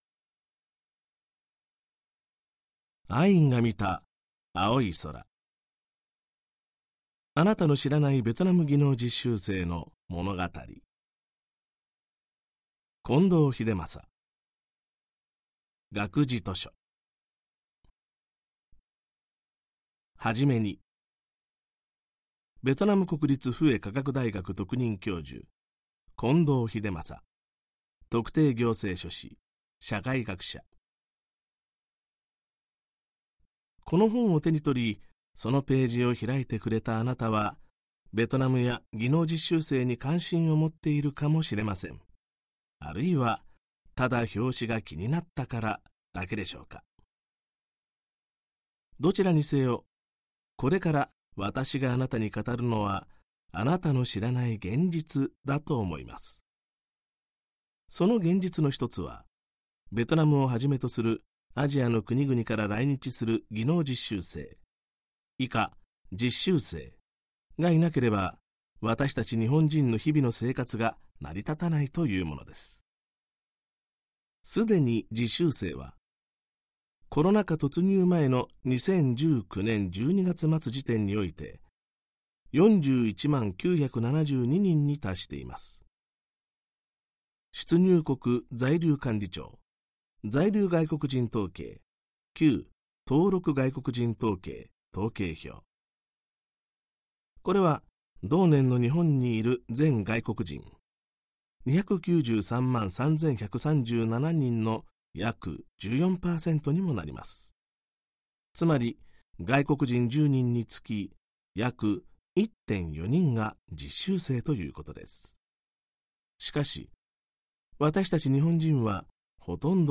[オーディオブック] アインが見た、碧い空。あなたの知らないベトナム技能実習生の物語